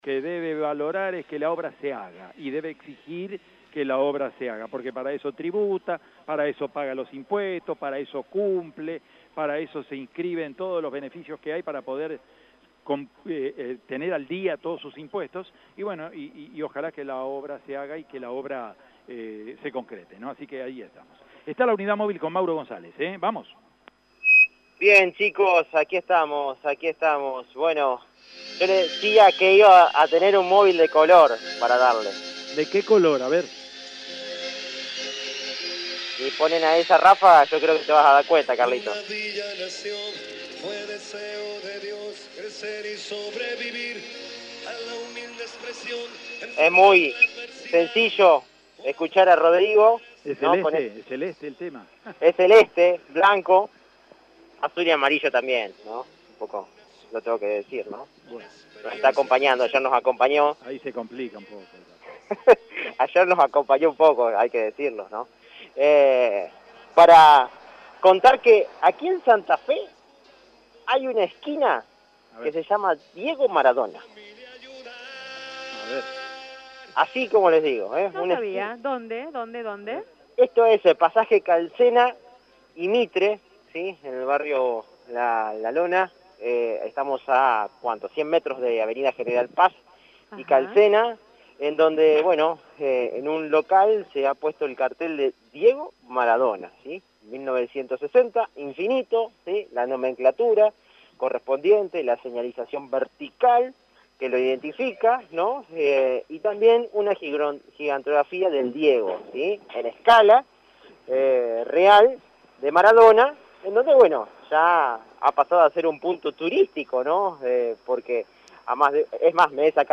«Mucha gente se dio cuenta cuánto lo quería a Diego y su muerte nos desgarró el alma, duele más que la pandemia. Queremos tenerlo siempre con nosotros a Diego, todo los días», aseguró uno de los dueños del local en dialogo con el móvil de Radio EME.